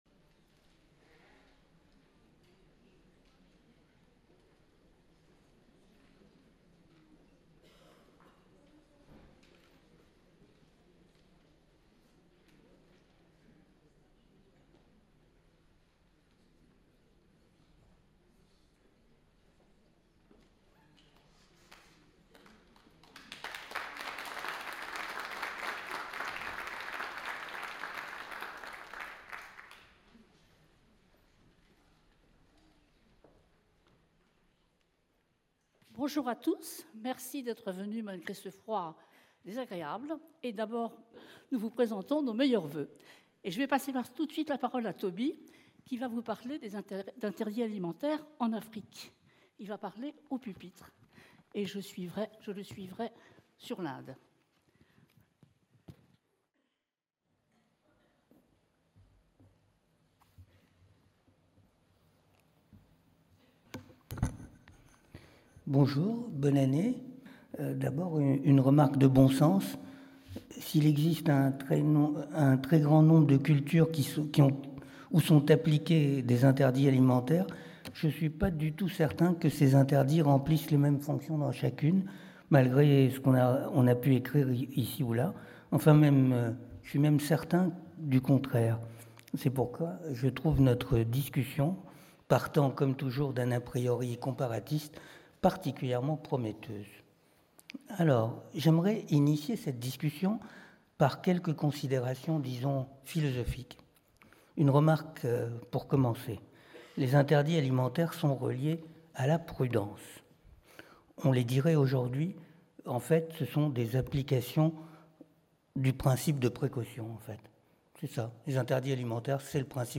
Au cours de cette confrontation, Catherine Clément et Tobie Nathan échangeront sur les interdits alimentaires en Afrique et en Inde par Catherine CLE
Conférence de l’Université populaire du quai Branly (UPQB), donnée le 04 janvier 2017 Cycle : DECALAGES : LES AUTRES ET NOUS Ce cycle aborde cette saison, la question de l'alimentation et de ses mythes, en confrontant différents codes et symboles selon les cultures et les sociétés.